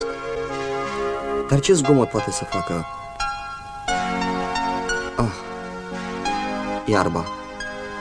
Tot prin orașul Băile Olănești, într-o ieșire din anul 2018, în prima zi de Rusalii.
dar-ce-zgomot-poate-sa-faca-iarba.mp3